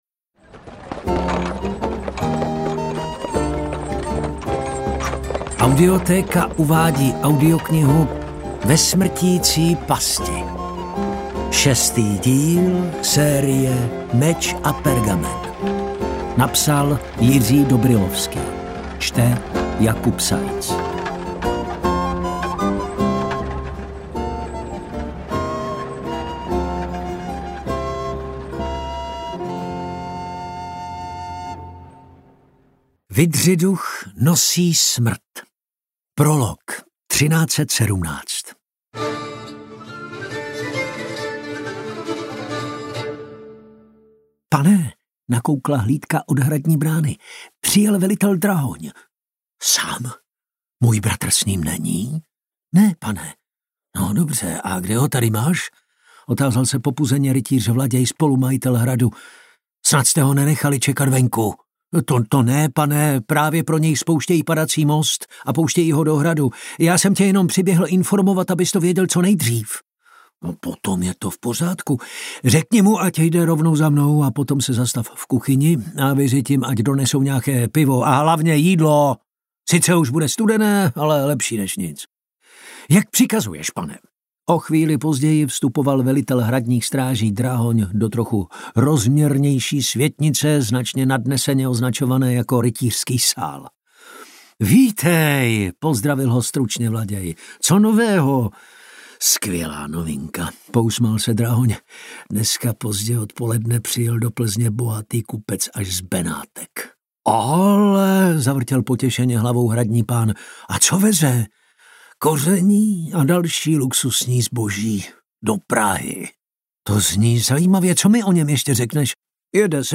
MP3 Audiobook